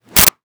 Sword Whip 04
Sword Whip 04.wav